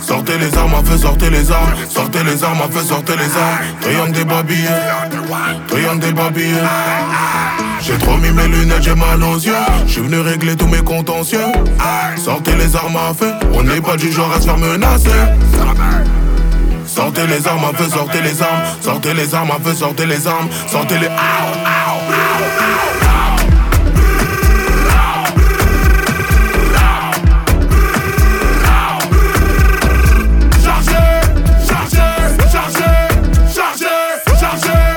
Afro-Beat African